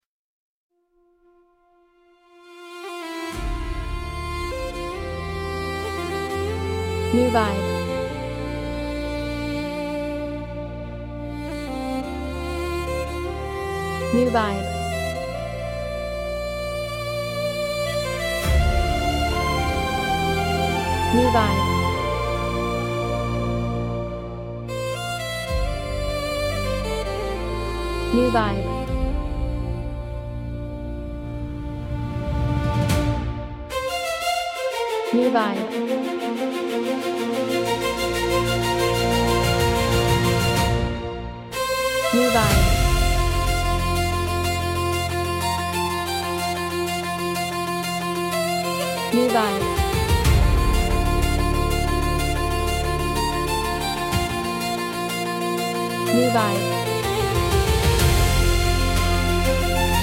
Genre: Medieval